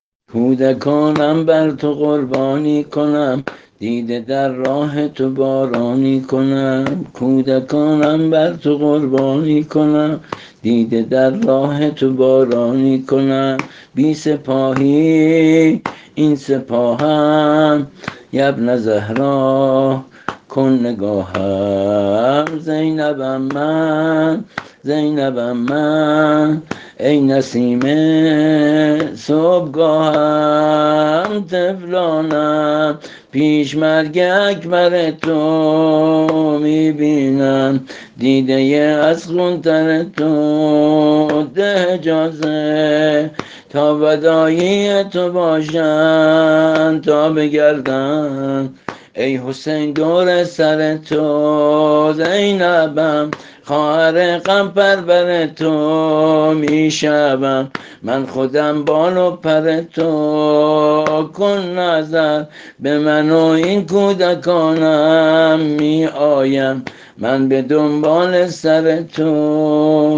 ◾شب چهارم محرم۱۳۹۸